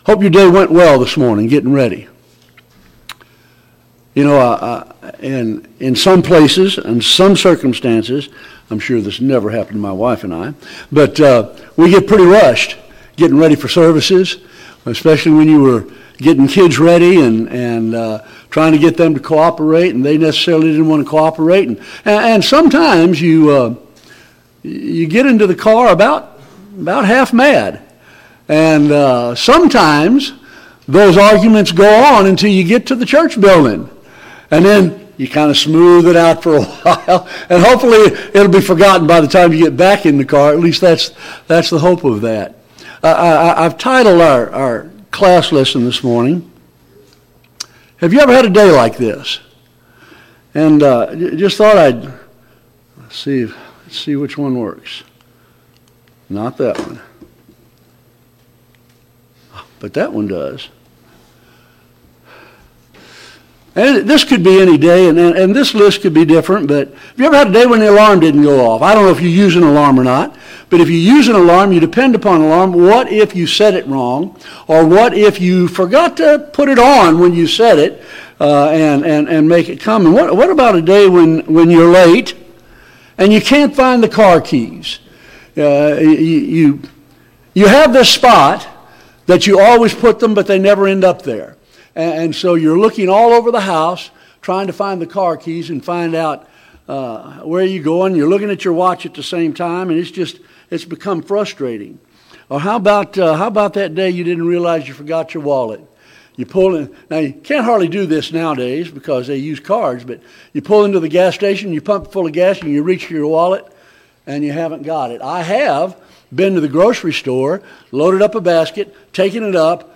2025 Fall Gospel Meeting Service Type: Gospel Meeting « 2.